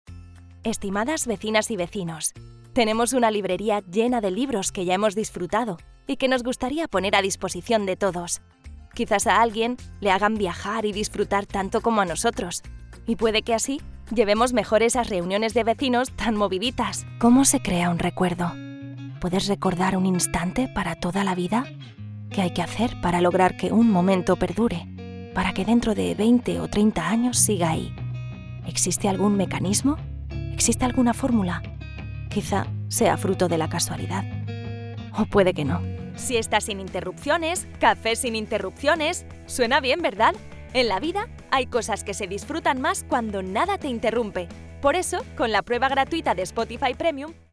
Commercial, Young, Natural, Friendly, Warm
Telephony